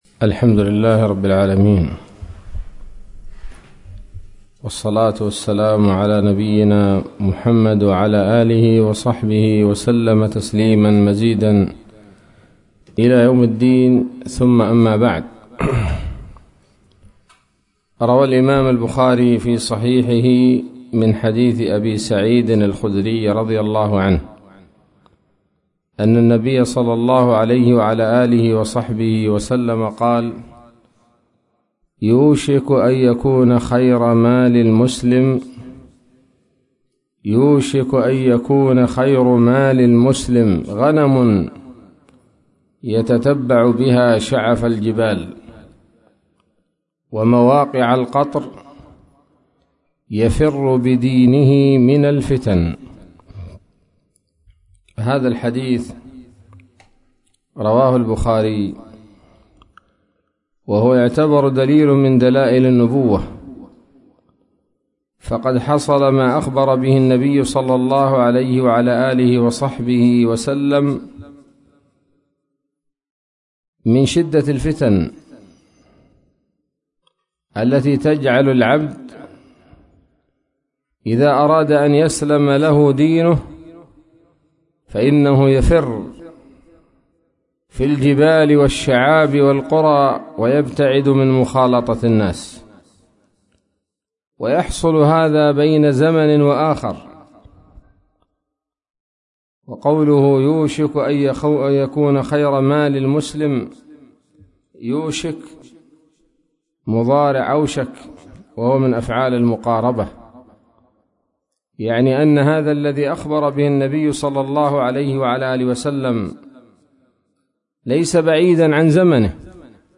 كلمة قيمة بعنوان: (( اعتزال الفتن غنيمة )) ظهر السبت 8 ربيع الآخر 1443هـ، بجزيرة ميون